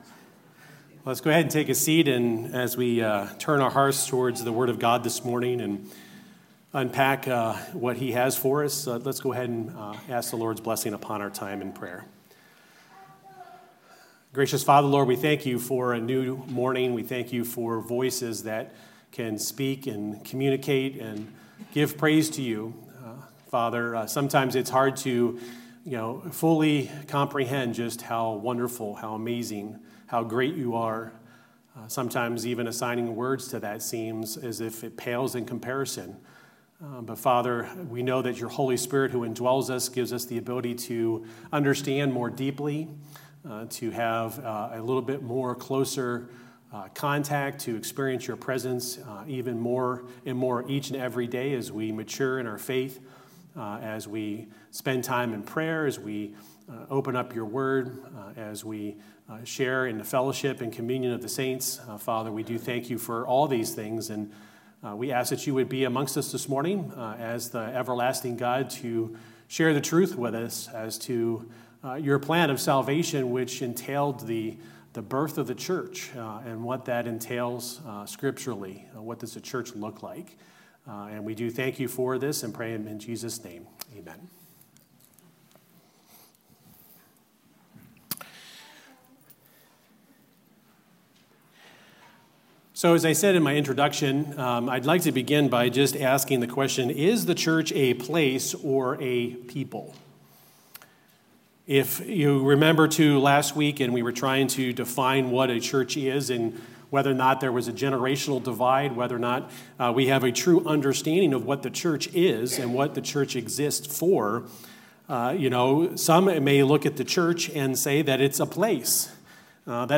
Sermons | Ellington Baptist Church